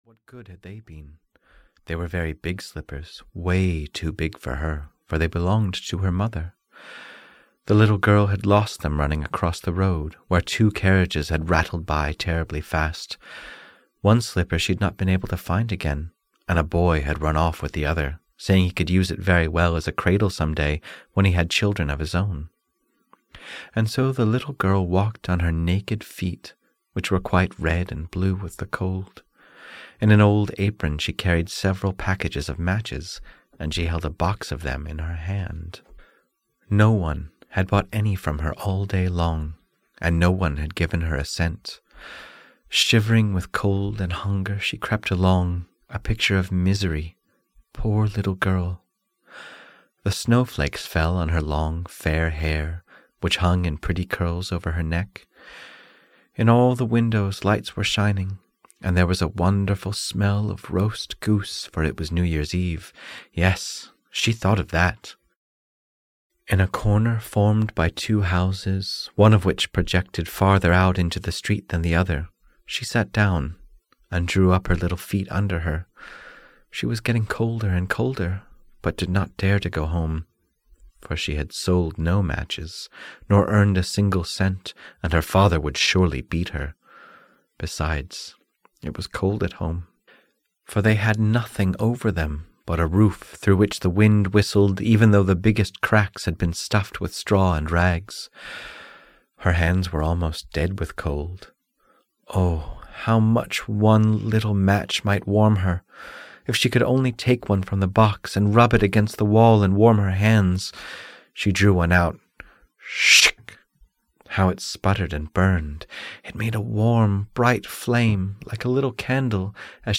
Christmas Fairy Tales (EN) audiokniha
Ukázka z knihy